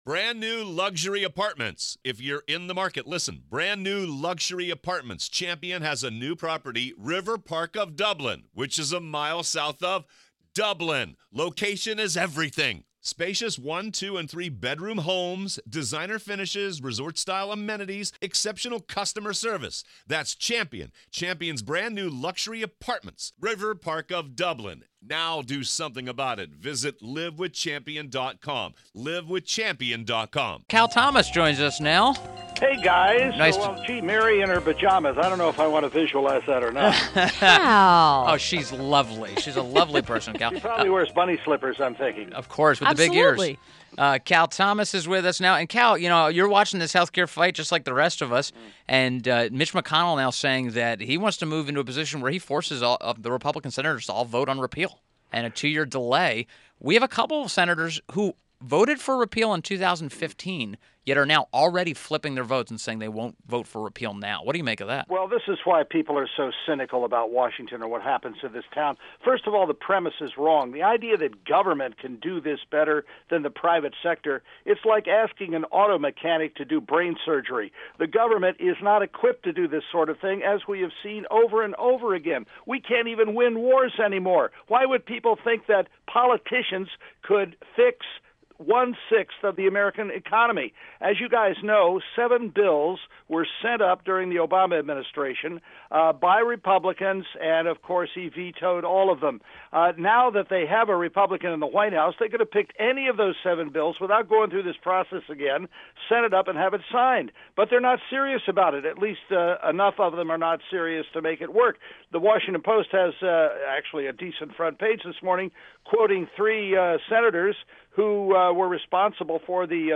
WMAL Interview - CAL THOMAS 07.19.17
CAL THOMAS – Syndicated columnist